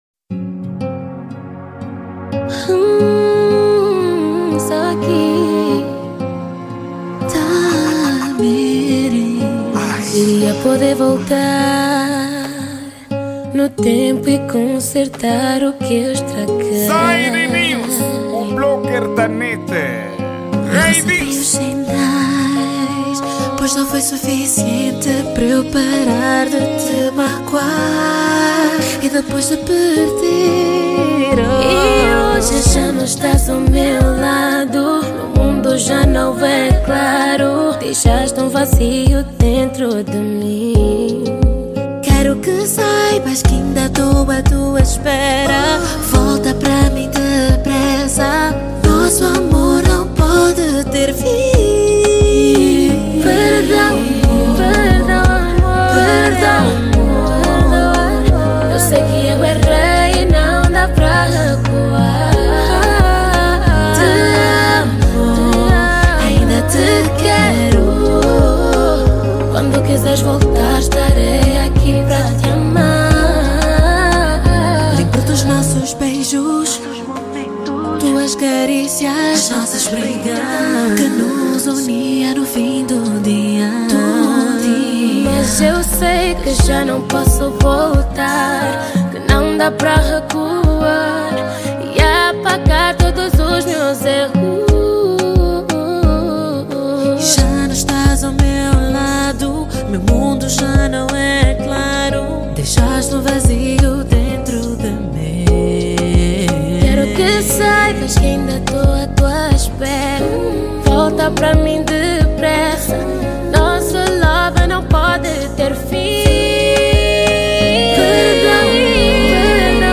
Gênero:Zouk